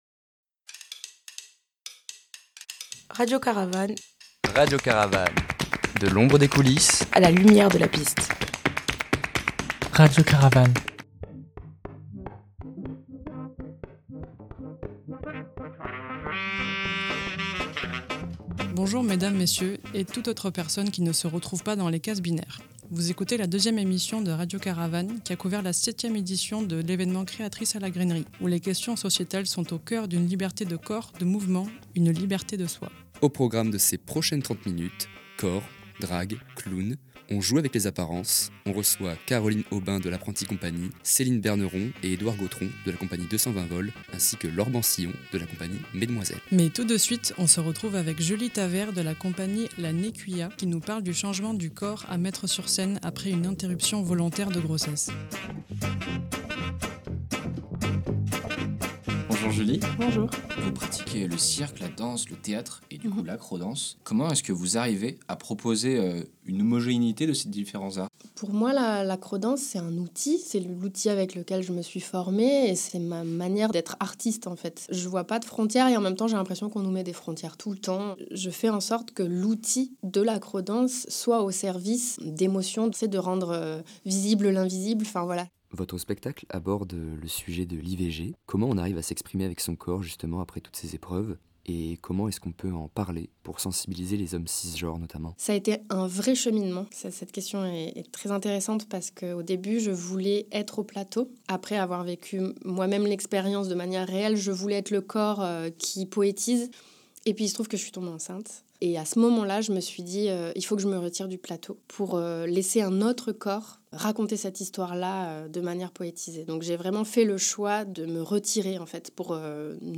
Nous avons couvert la septième édition de l’événement « Créatrices » à la Grainerie, qui a mis à l’honneur les créations autour des libertés de genres, de corps, de consentir ou pas…